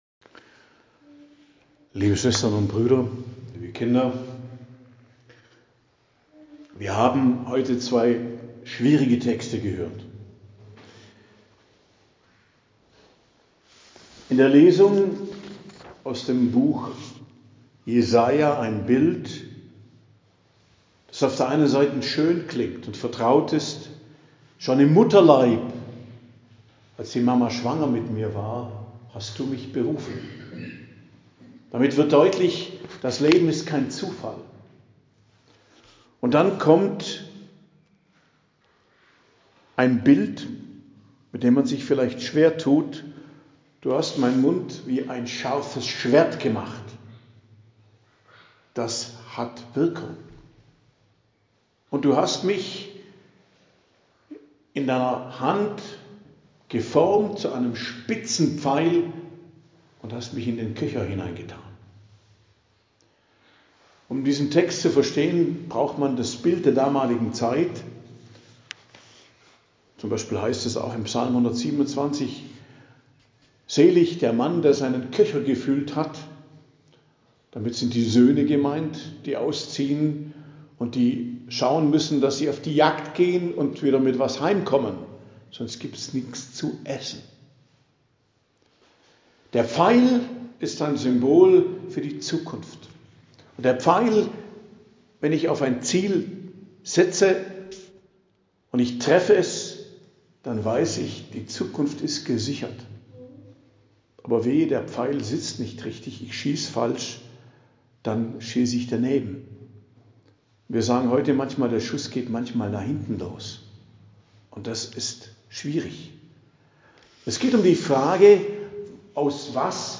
Predigt am Dienstag der Karwoche, 15.04.2025 ~ Geistliches Zentrum Kloster Heiligkreuztal Podcast